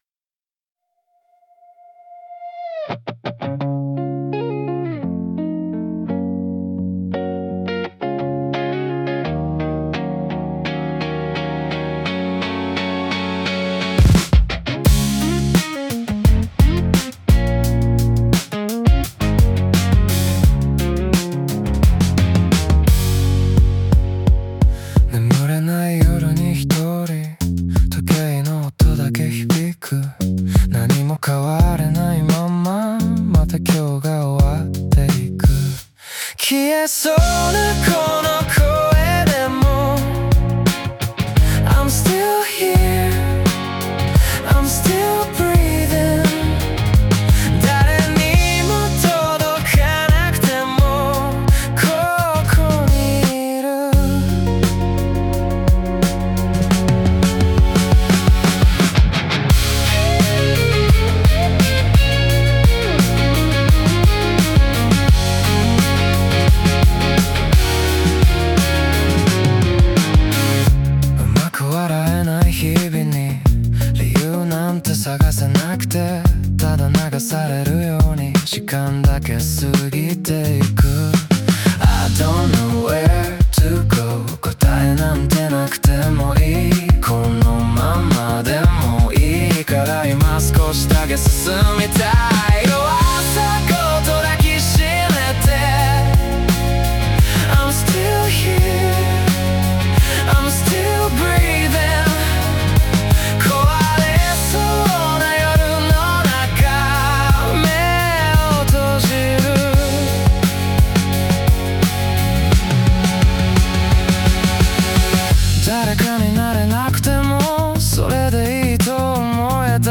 男性ボーカル
イメージ：サイケデリック,インディーオルタナティブ,男性ボーカル